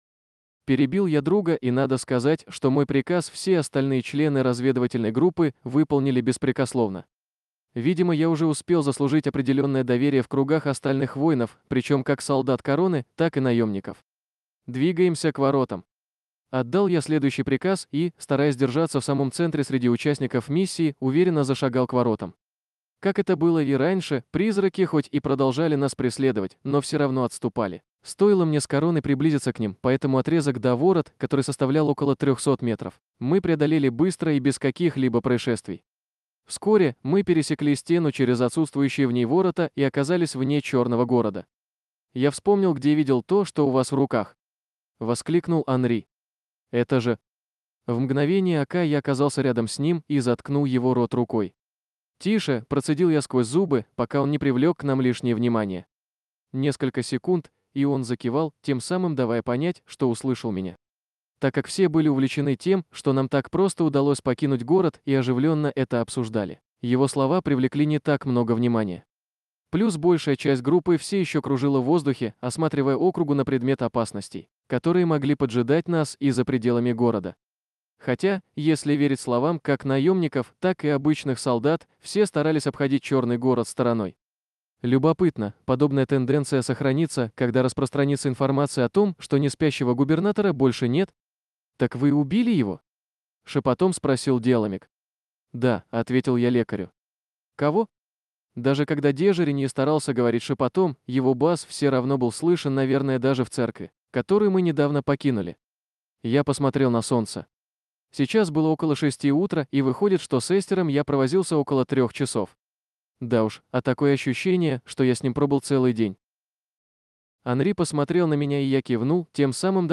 Аудиокнига Кровь Василиска 4
Качество озвучивания весьма высокое.